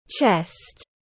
Ngực tiếng anh gọi là chest, phiên âm tiếng anh đọc là /tʃest/.
Chest /tʃest/